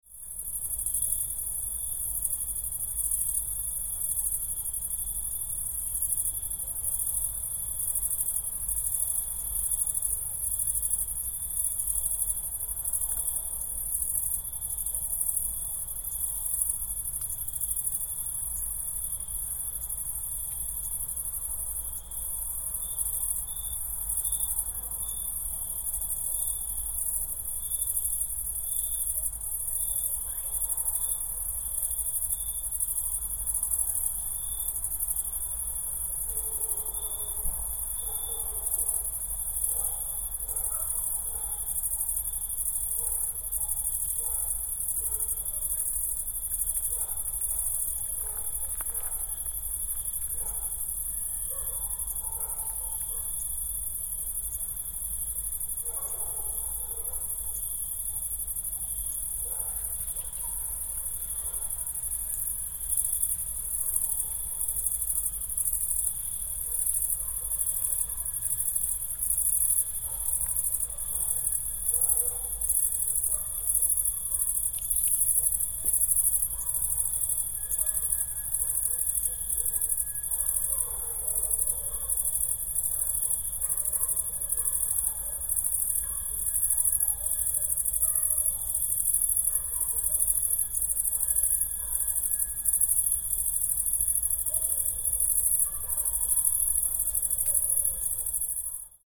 Relaxing Sounds / Sound Effects 5 Jan, 2026 Crickets Chirping In Summer Night Sound Effect Read more & Download...
Crickets-chirping-in-summer-night-sound-effect.mp3